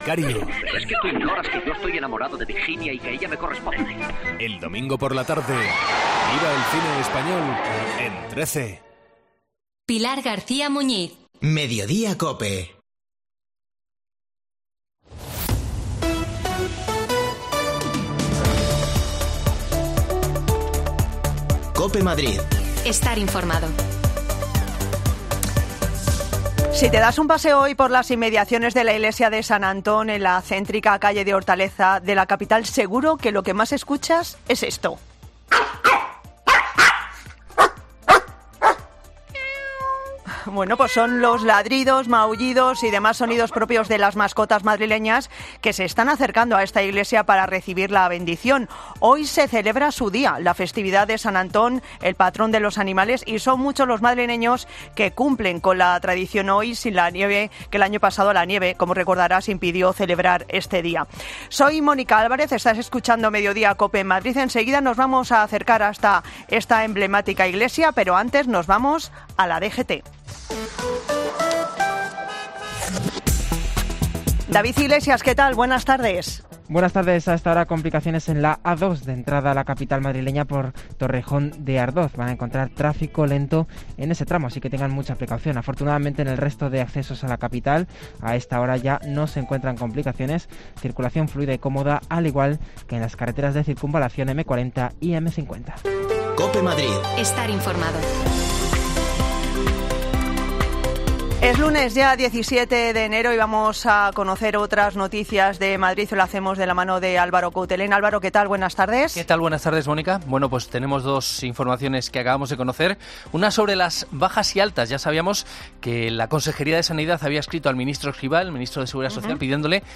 Nos acercamos por la iglesia de San Antón para hablar con dueños y mascotas...